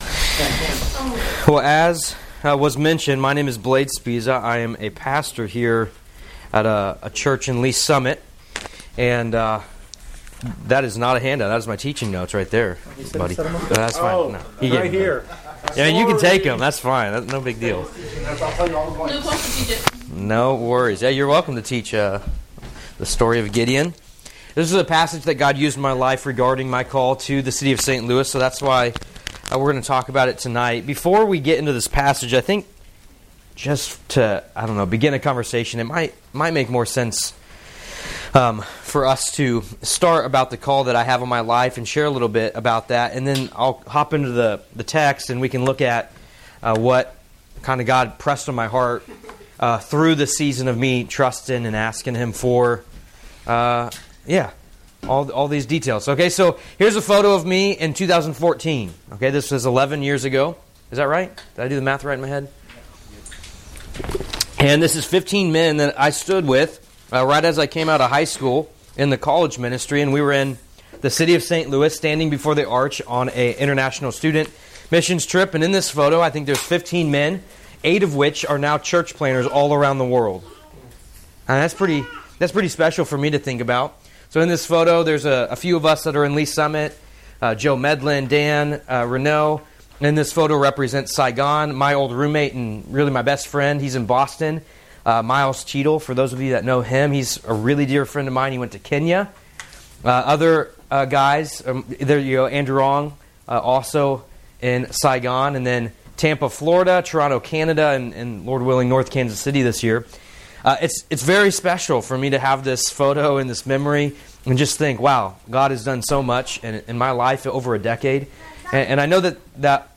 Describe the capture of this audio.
This is a great time of followship, with a more laidback environment, giving the youth the chance to ask questions to the missionaries; about the mission field and how they’re following the call to "GO!" (Matt 28:19). Also, hearing personal testimonies and experiences from the missionaries.